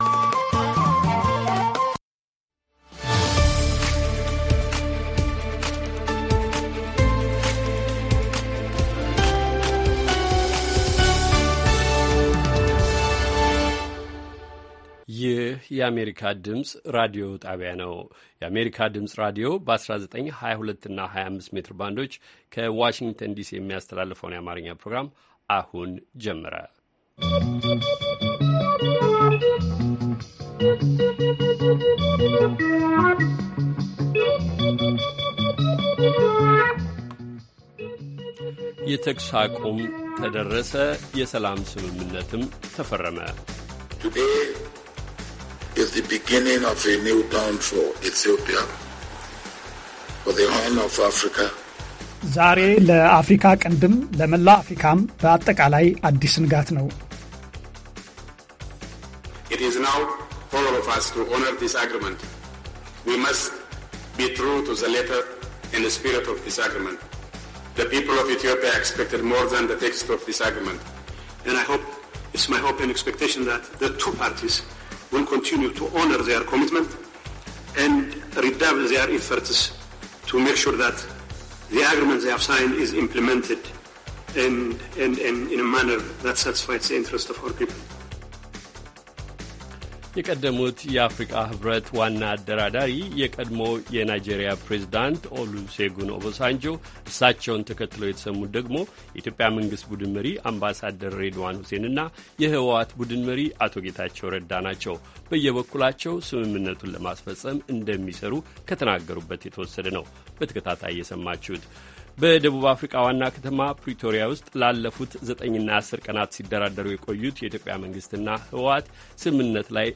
ረቡዕ፡-ከምሽቱ ሦስት ሰዓት የአማርኛ ዜና